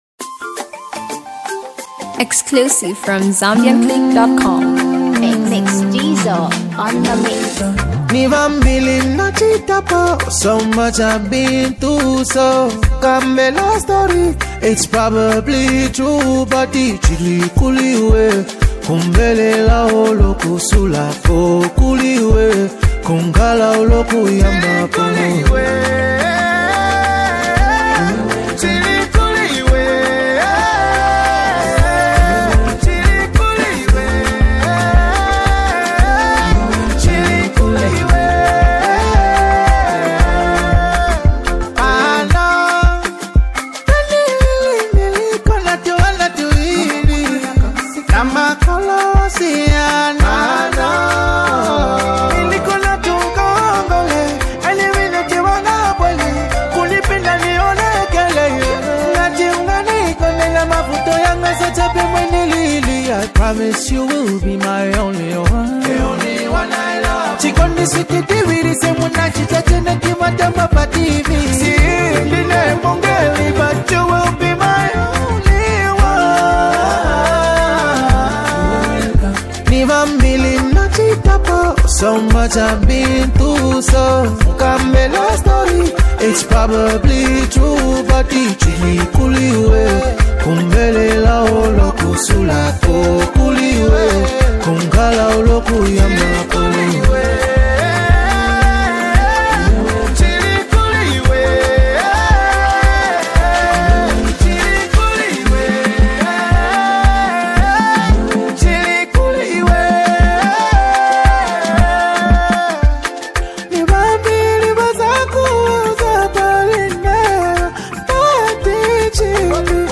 RnB